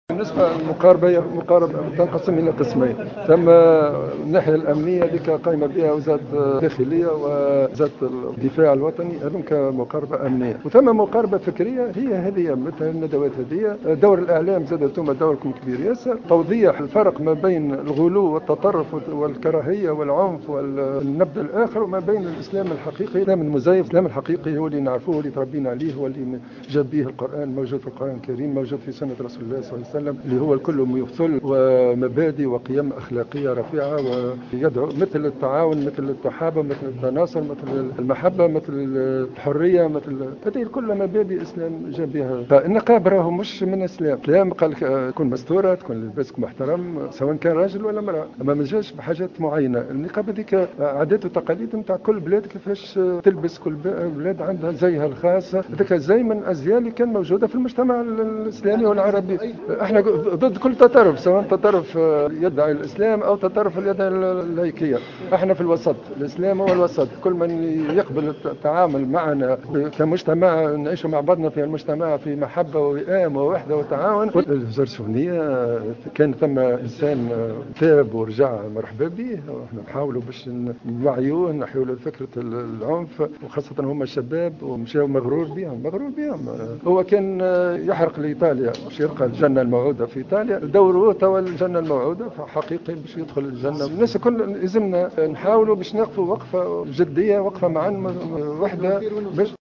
أكد وزير الشؤون الدينية عثمان بطيخ خلال الندوة الوطنية الأولى بخصوص اشكالية المصطلح الشرعي و خطورة التوظيف الجهاد و التكفير أنموذجا التي انعقدت اليوم بالعاصمة أن مقاربة مقاومة الإرهاب تنقسم الى قسمين أولها المقاربة الأمنية التي تعد من مشمولات وزارة الداخلية والدفاع والمقاربة الفكرية التي تعد من مشمولات وزارة الشؤون الدينية والأيمة والخطاب الذي يوجهونه للمتلقي .